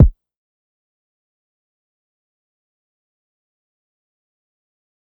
BOOMIN KICK 1.wav